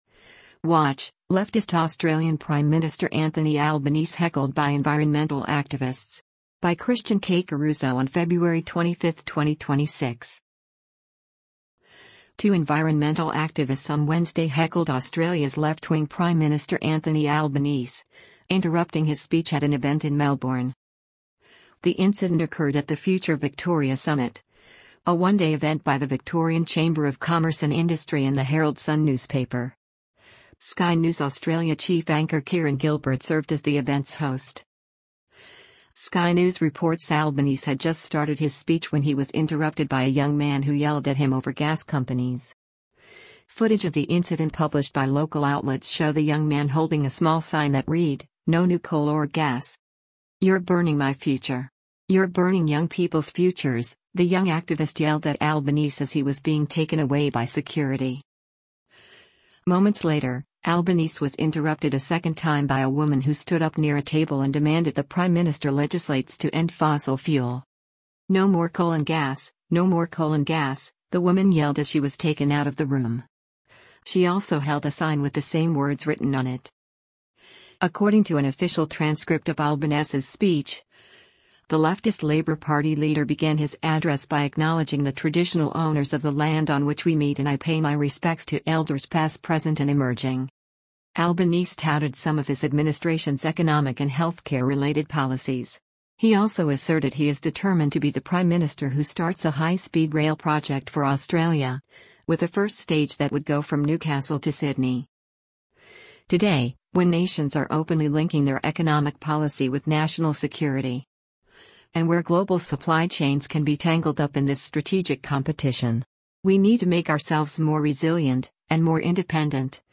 Two environmental activists on Wednesday heckled Australia’s left-wing Prime Minister Anthony Albanese, interrupting his speech at an event in Melbourne.
Sky News reports Albanese had just started his speech when he was interrupted by a young man who yelled at him over gas companies.
Moments later, Albanese was interrupted a second time by a woman who stood up near a table and demanded the prime minister legislates “to end fossil fuel.”
“No more coal and gas, no more coal and gas,” the woman yelled as she was taken out of the room.